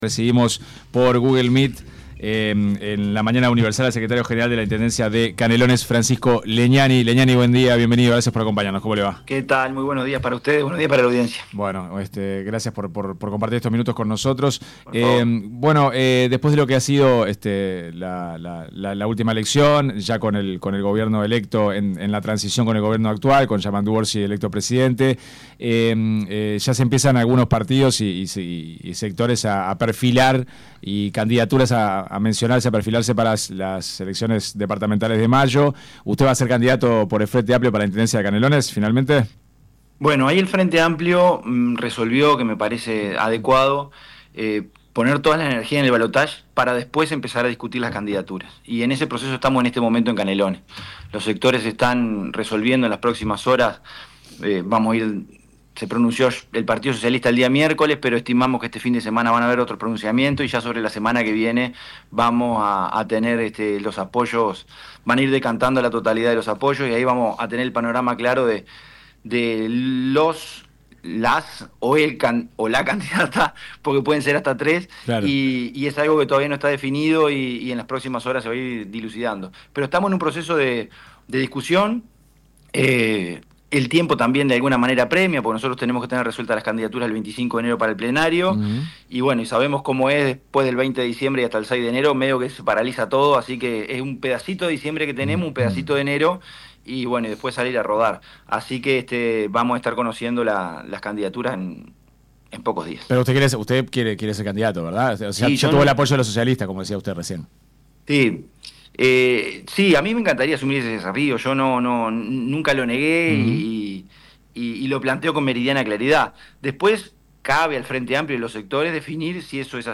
LEGNANI-entrevista.mp3